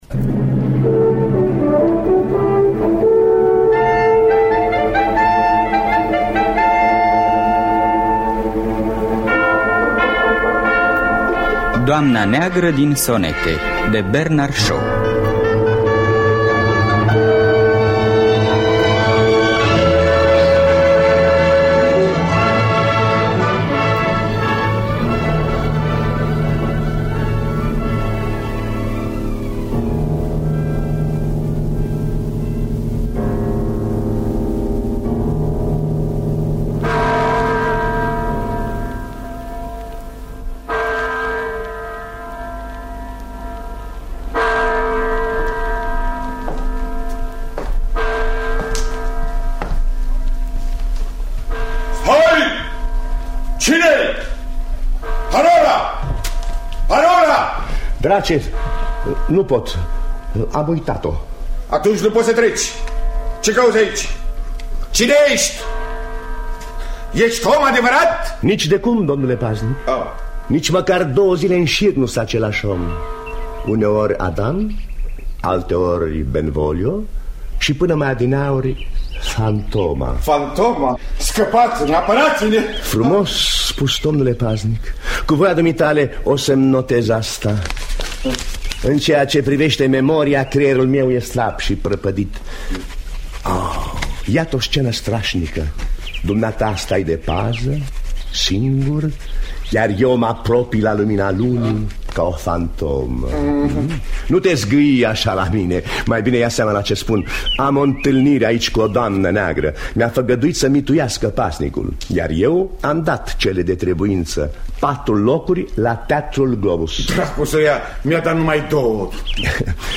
În distribuţie: Marcel Anghelescu, Fory Etterle, Dina Cocea, Stela Popescu.